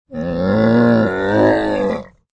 Camel 8 Sound Effect Free Download